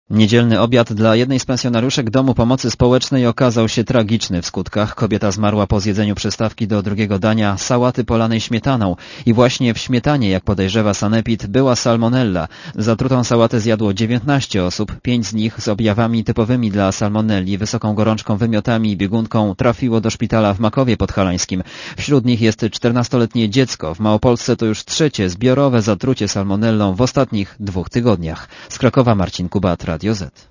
Relacja reportera Radia ZET